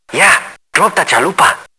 When you squeeze it, the Taco Bell dog blurts out "Drop the chalupa!".
It's just a little pink box (why pink?!) with 4 watch batteries, a little glop top with a reddish brown covering, pushbutton, and speaker.